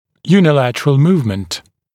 [juːnɪ’lætərəl ‘muːvmənt][йу:ни’лэтэрэл ‘му:вмэнт]одностороннее перемещение